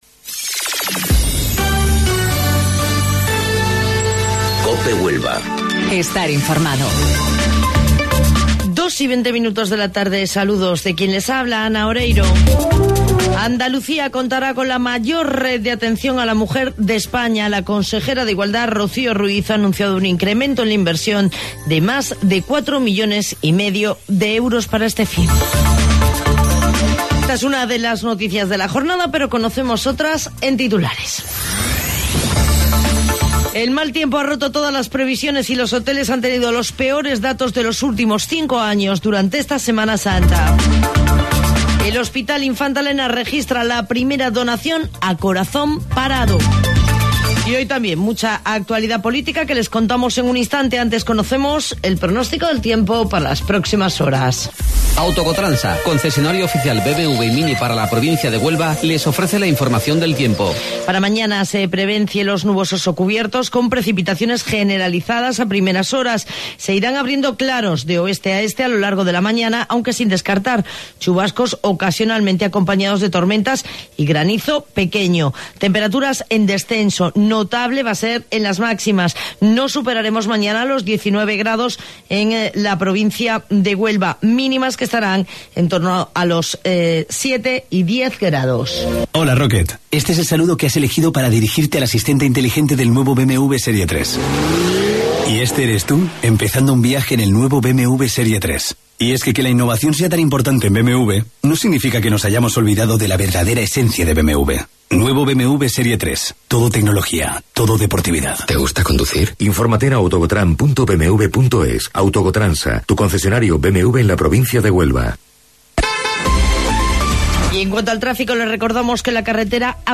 AUDIO: Informativo Local 14:20 del 22 de Abril